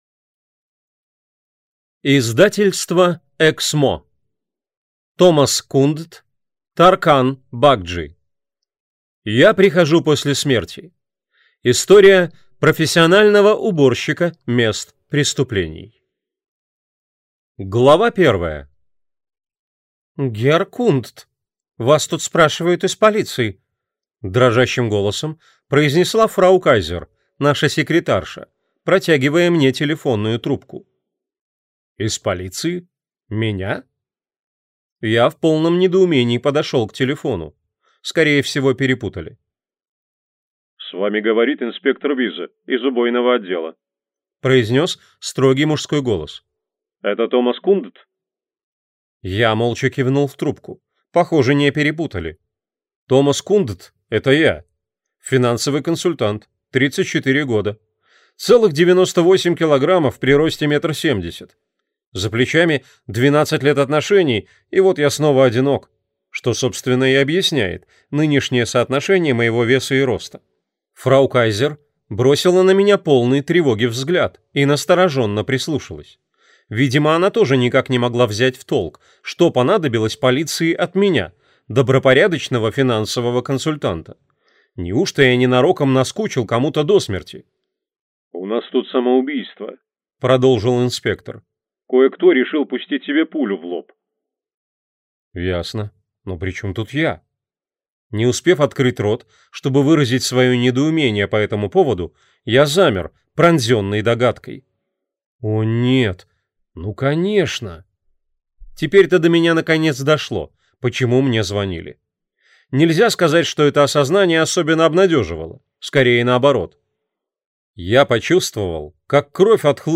Аудиокнига Я прихожу после смерти. История профессионального уборщика мест преступлений | Библиотека аудиокниг